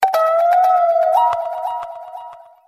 • Качество: 128, Stereo
Музыкальная шкатулка
загадочные